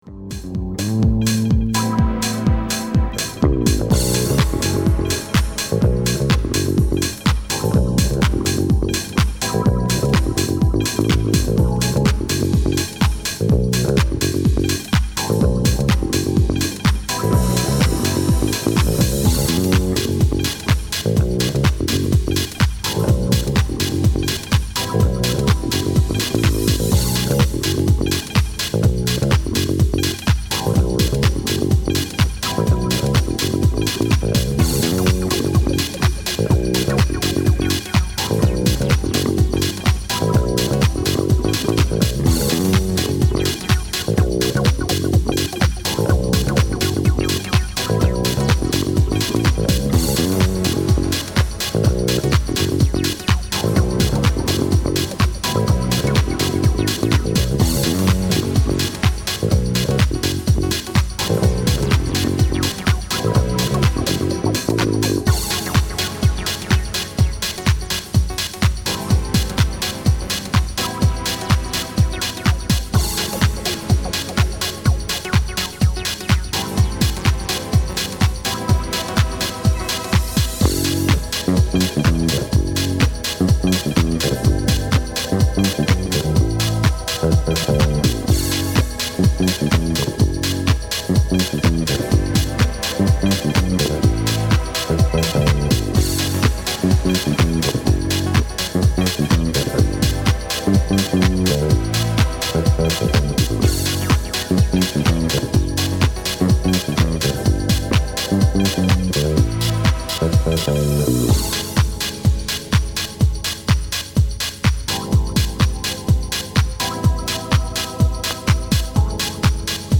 心地よく弧を描くようなシンセワークが魅力的です。モダンフュージョンのテイストも伺える、当店マスターピース。